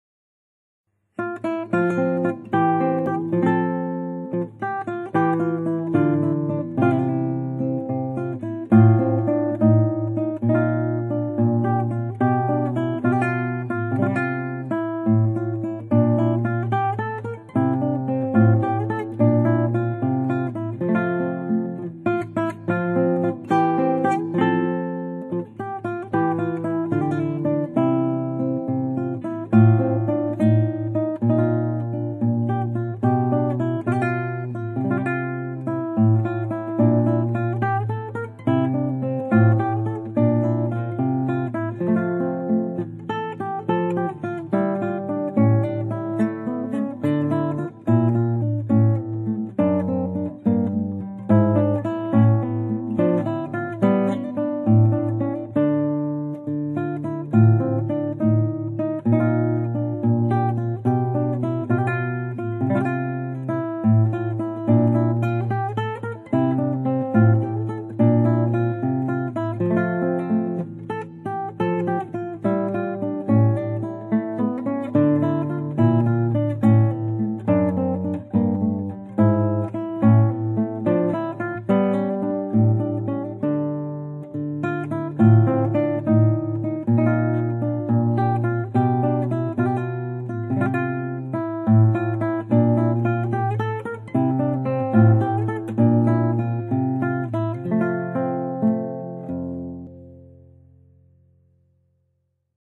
Baroque Guitar 60bpm